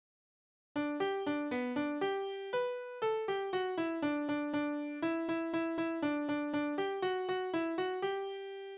Kindertänze: Familie spielen
Tonart: G-Dur
Taktart: 2/4
Tonumfang: Oktave
Besetzung: vokal